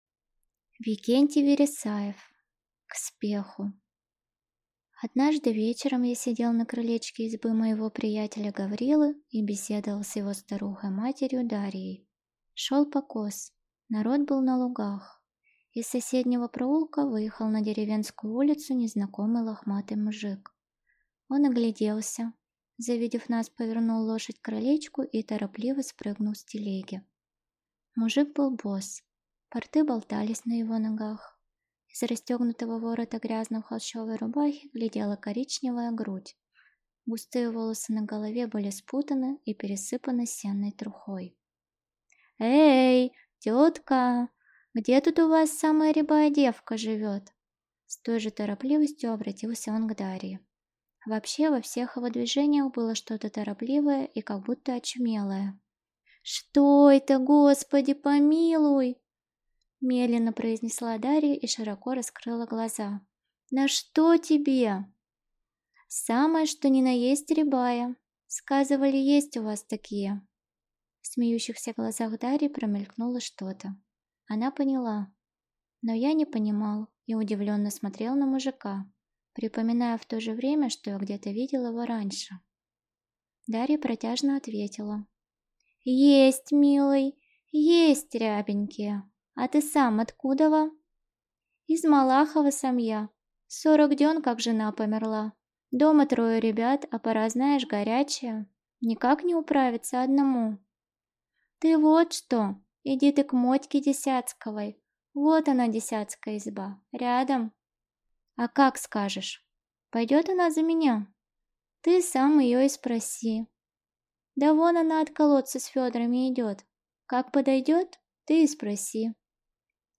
Аудиокнига К спеху | Библиотека аудиокниг